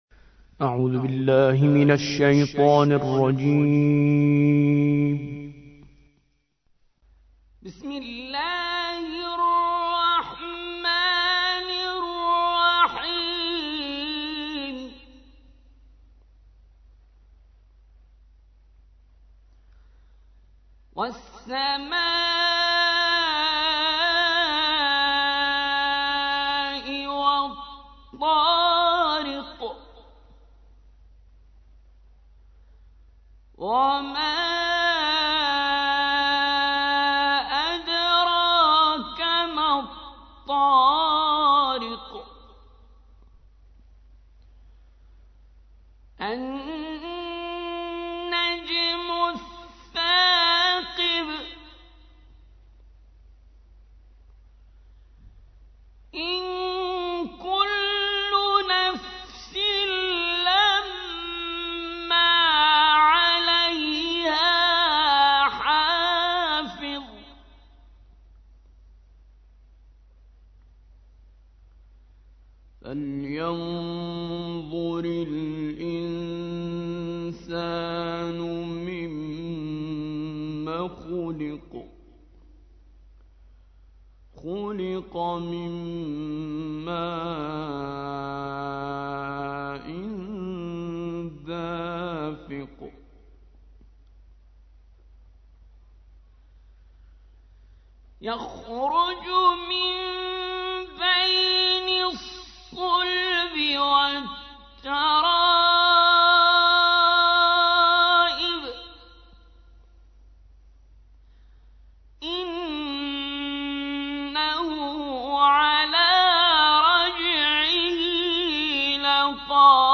86. سورة الطارق / القارئ